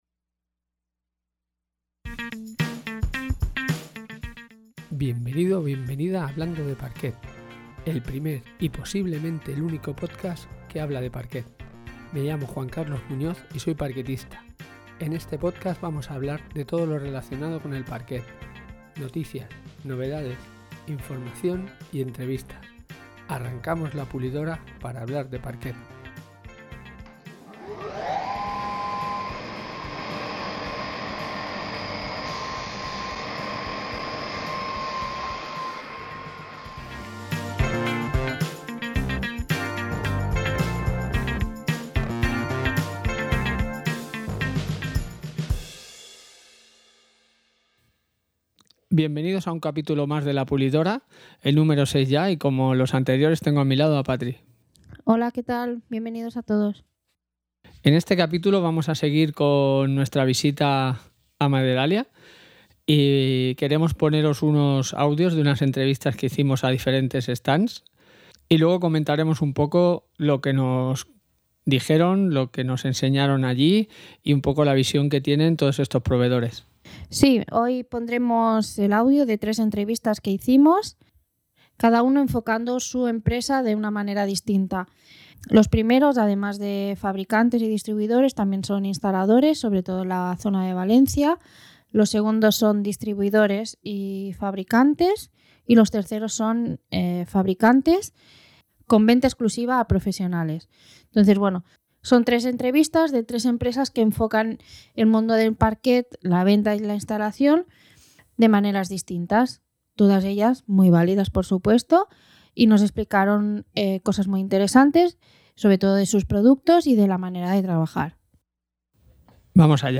En el capítulo de esta semana de 'la pulidora' #6 hablando de parquets en Maderalia, os ofrecemos una serie de pequeñas entrevistas que realizamos en la feria de Maderalia 2022 con varios distribuidores de pavimentos, diferentes visiones del mundo parquet, tipos de parquets y su distribución, y nuevos productos ...